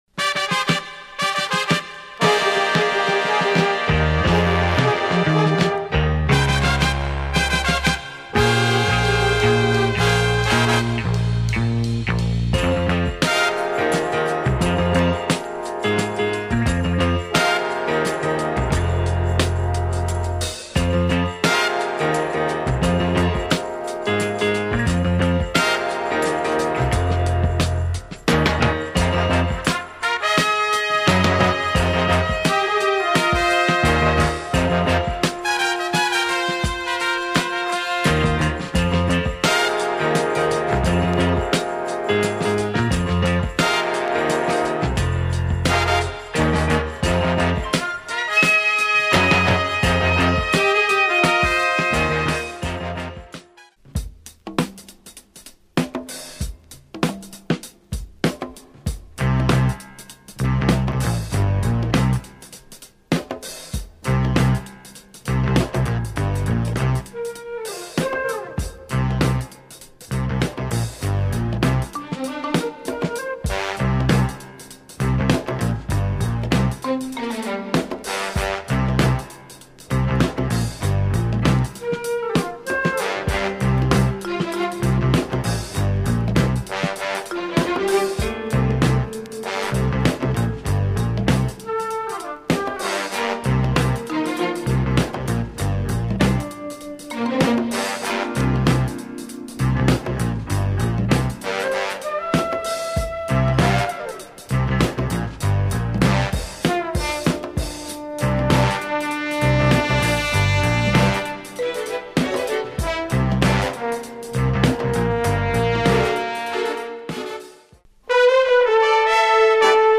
tough drumbreak (2 bars) then breaks and stabs
afro-pop groove with good beats
dope breaks with strings
killer drama breaks + electro
pure drama funk with terrific breaks